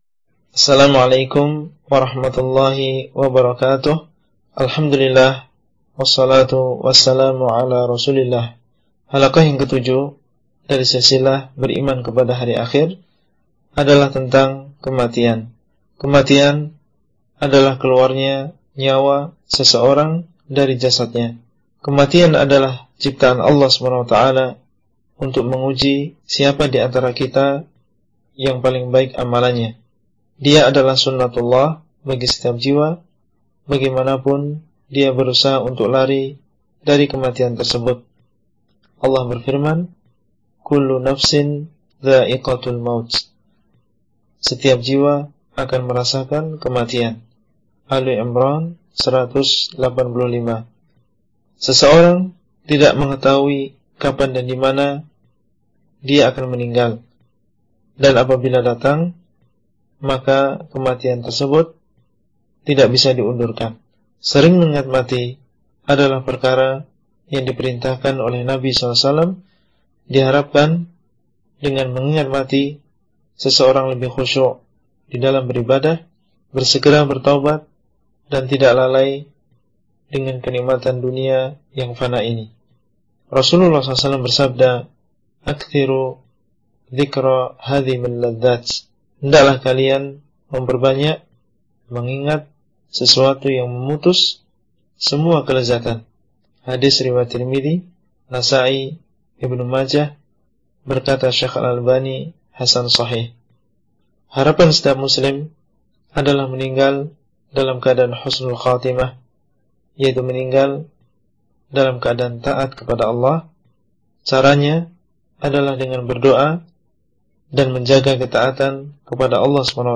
Halaqah – 01 Makna dan Dalil Beriman Kepada Hari Akhir